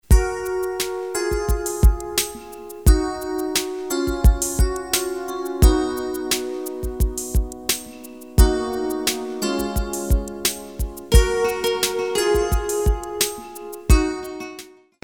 Jazz Music Samples
Jazz 08a